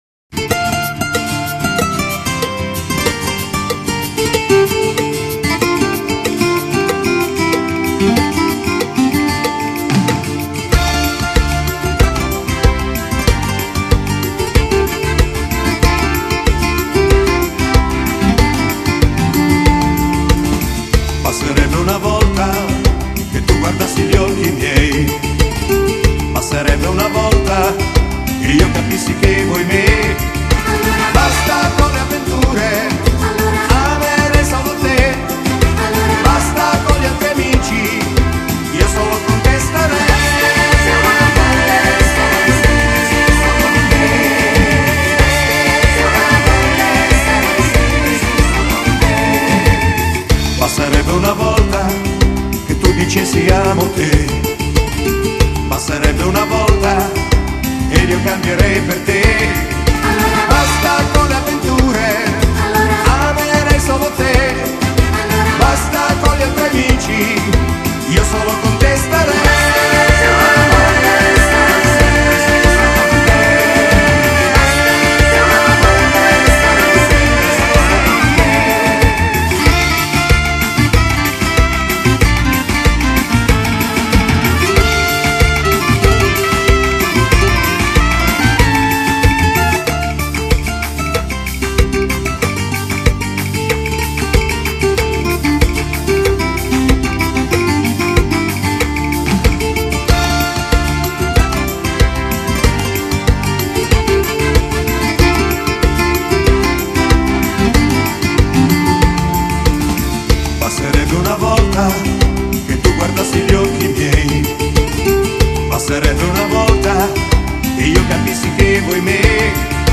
Genere: Cumbia